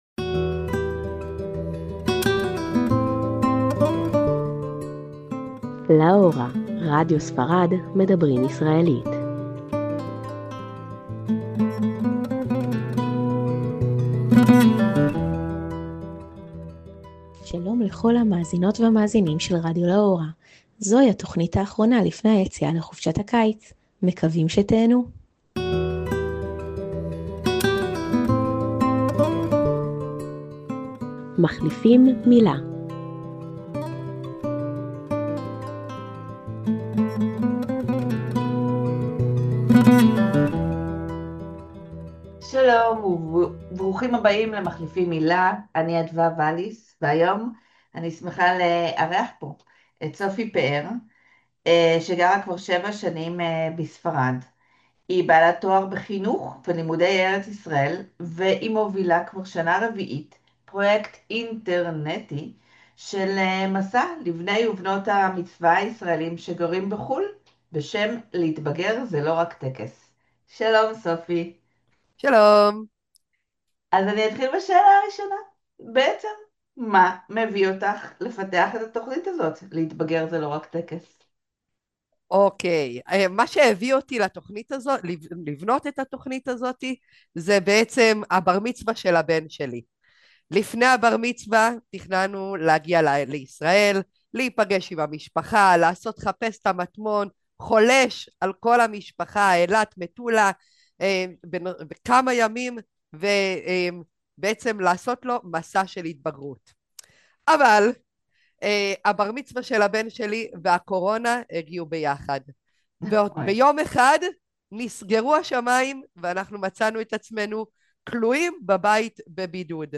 "LA HORA": MEDABRÍM ISRAELÍT - לה הורה״ – תכנית רדיו בעברית לטובת הישראלים בספרד" והיום - * "מחליפים מילה" – והיום על מסע עולמי ומרתק לנוער בשנת המצווה.